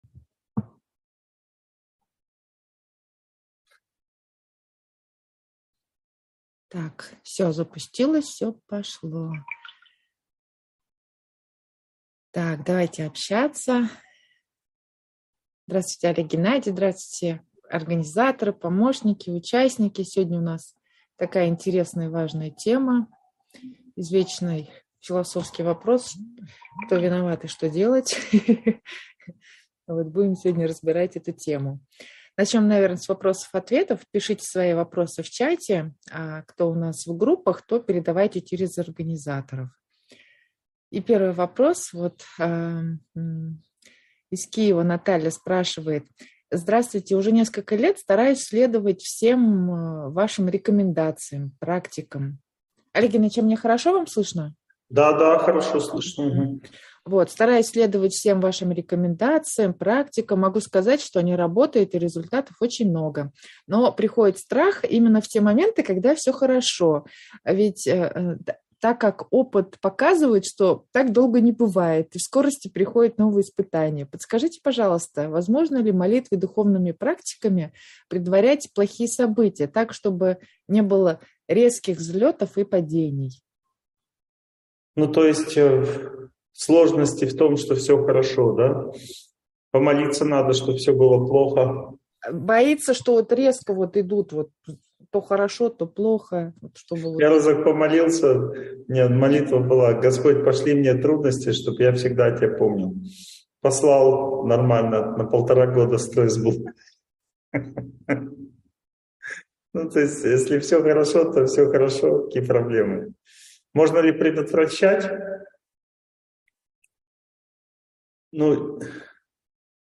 Кто виноват и что делать? Нужно ли искать причину своих несчастий? (онлайн-семинар, 2022)